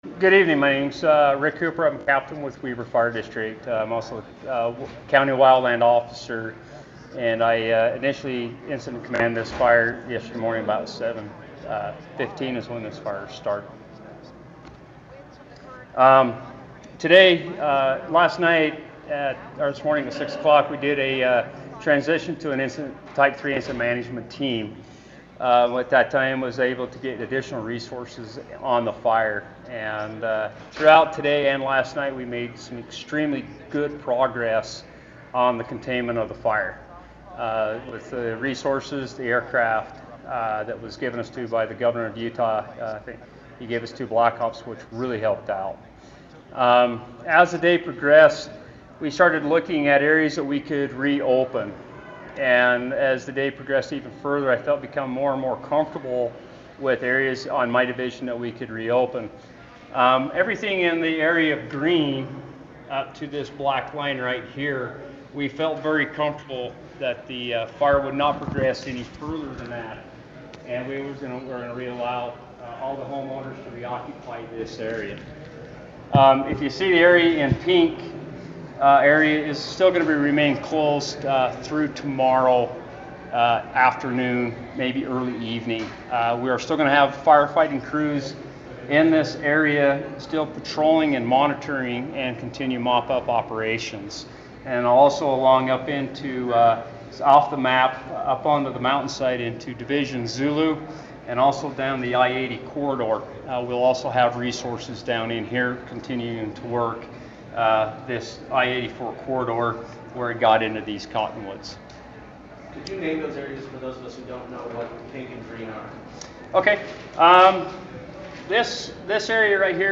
Fire managers on the Uintah Fire provided an update to reporters on Wednesday afternoon from the Dee Events Center.